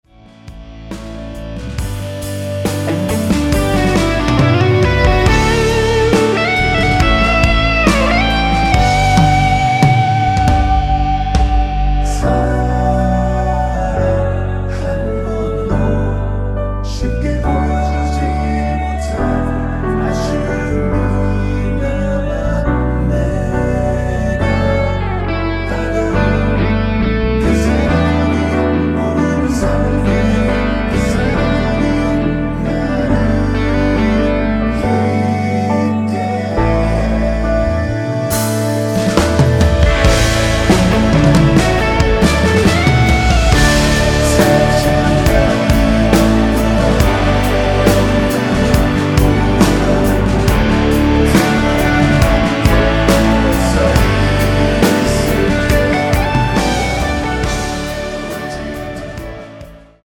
원키에서(-2)내린 멜로디와 코러스 포함된 MR입니다.(미리듣기 확인)
노래방에서 노래를 부르실때 노래 부분에 가이드 멜로디가 따라 나와서
앞부분30초, 뒷부분30초씩 편집해서 올려 드리고 있습니다.